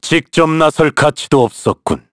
Shakmeh-Vox_Victory_kr_a.wav